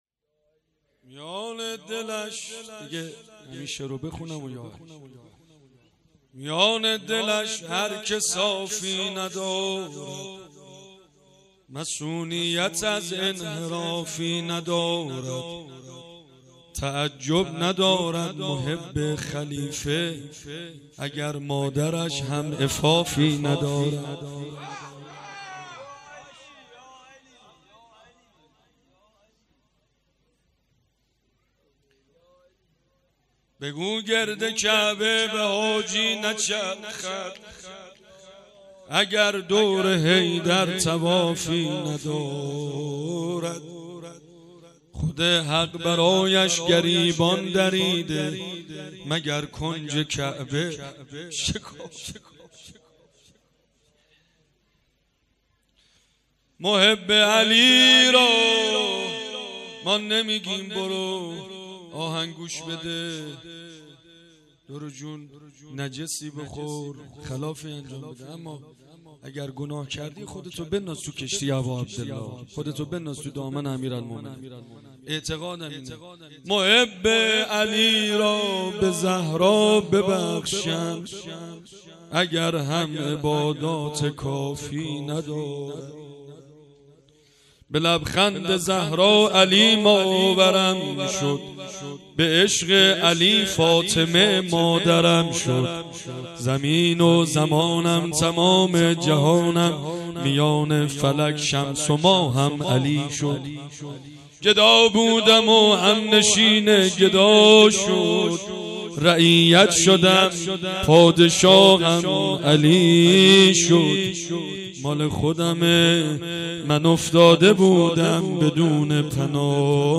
مناجات و روضه
شب دوم جشن عید غدیر ۱۴۰۴